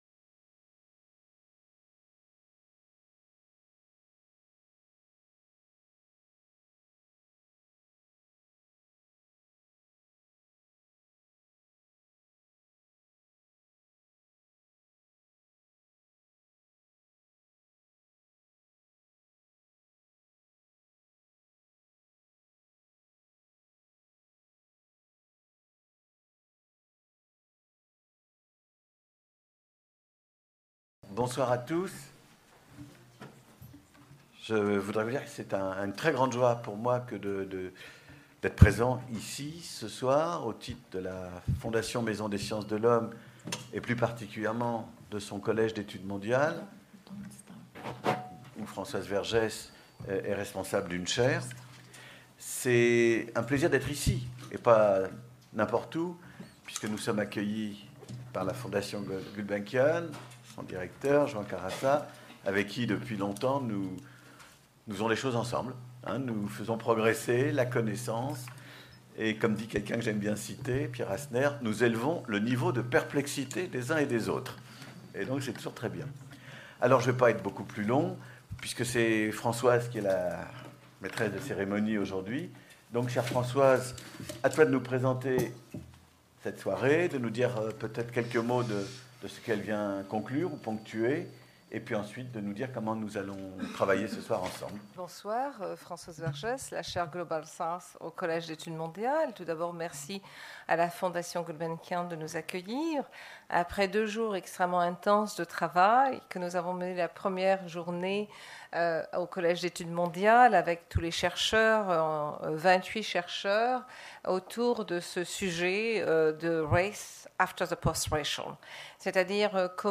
Race After the Post-Racial Thinking Through the Proliferation of Racisms Table ronde | Canal U
Cette table-ronde, qui clôt un workshop de deux jours qui s’est déroulé au Collège d’études mondiales de la Fondation Maison des sciences de l’homme, réunit des intellectuels et des activistes afin de partager une réflexion qui puisse devenir aussi prise de conscience et action .